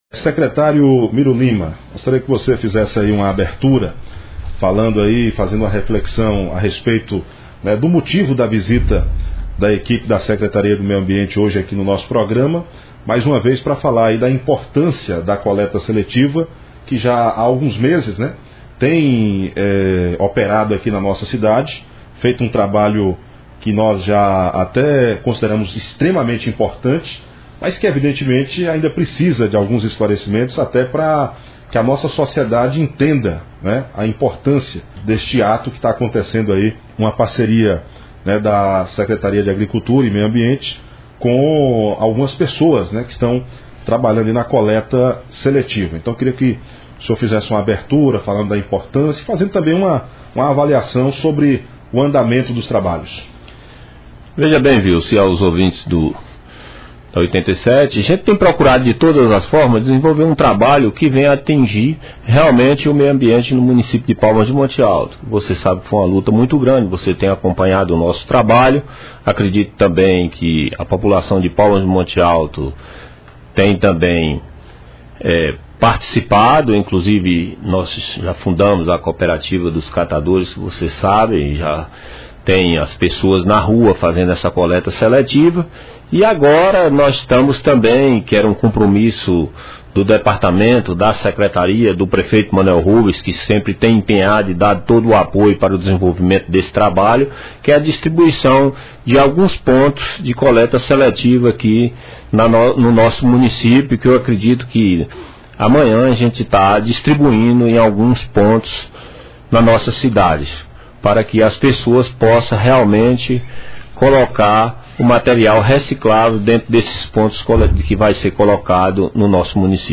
Em relação a implantação dos PEVs, a equipe manifestou que isso tornará a coleta seletiva mais acessível a todos, pois se nem sempre ela é realizada no sistema porta a porta, a presença de PEVs pode fazer a diferença para que as embalagens pós-consumo sejam corretamente encaminhadas à reciclagem, em vez de acabarem ocupando espaços indevidos. OUÇA A ENTREVISTA: